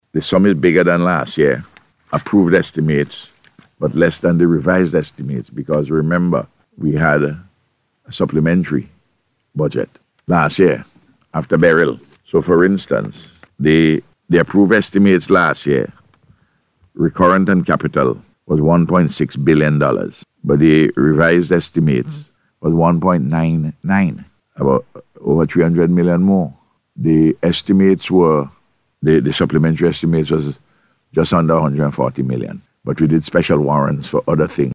Prime Minister Dr Ralph Gonsalves, speaking on NBC radio, says the sum of the approved estimates for 2025 is approximately 235 million dollars more than the approved estimates for last year.